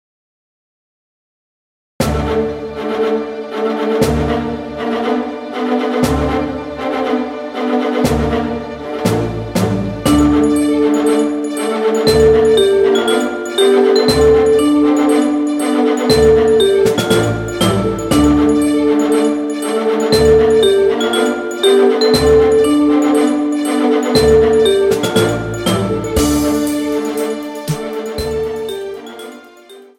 Strings - Demonstration